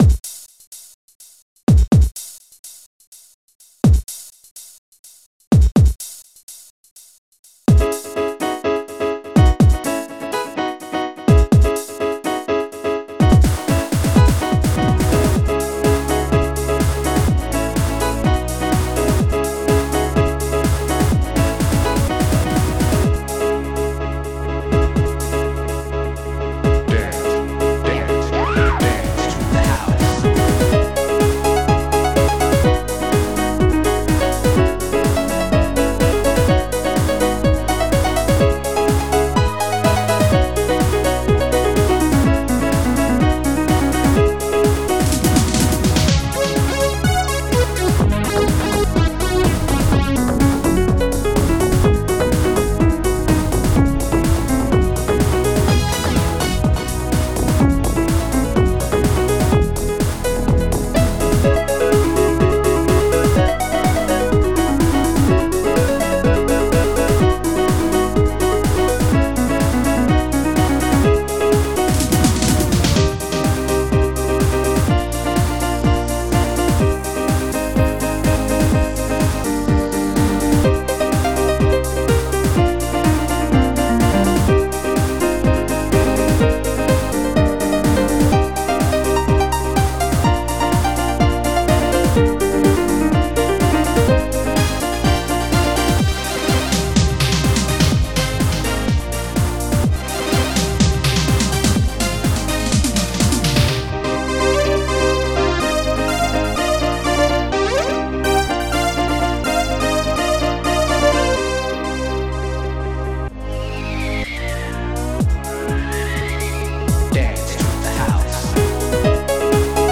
Protracker and family
openhihat
tecnobass
piano1
brasslead
stringlead